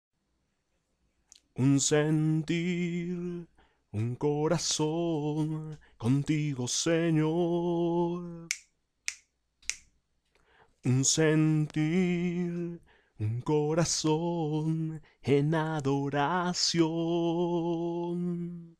3a Voz Precoro Hombre